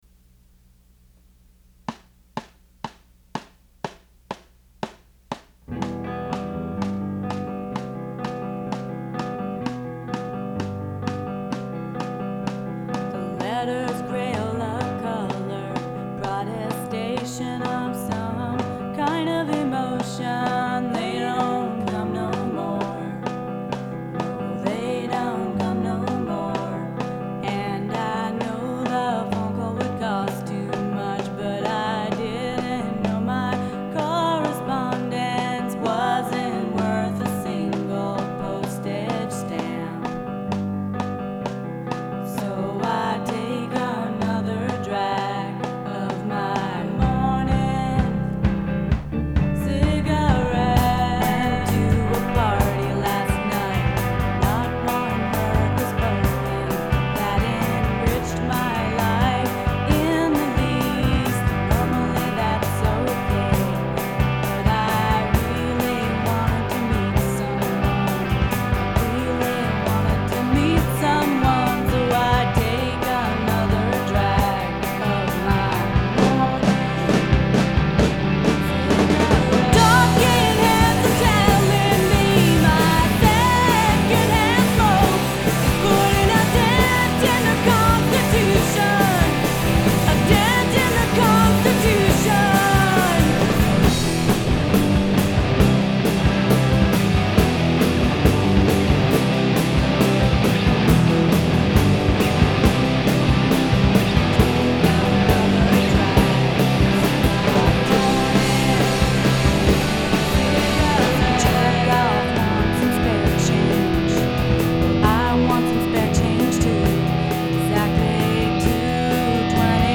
Recorded August 1994 at Dessau, NYC